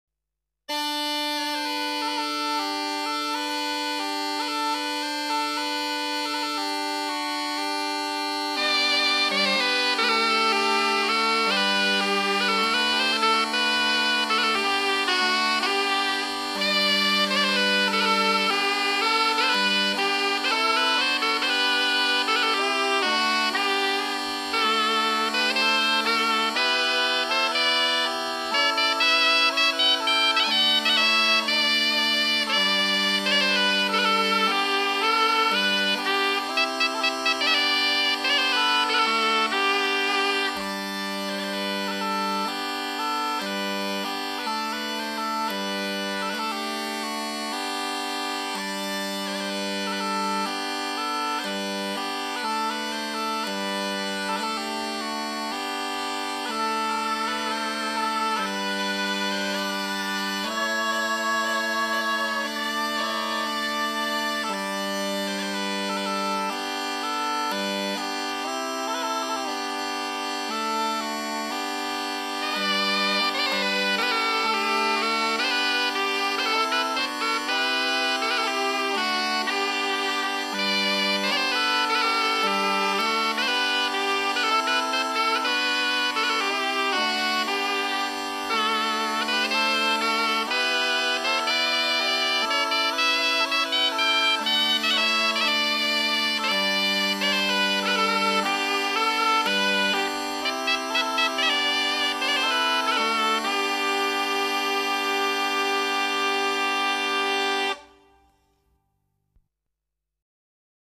La zampogna molisana
La zampogna emette un suono stridente e tremulo ed è, di solito, suonata all'aperto.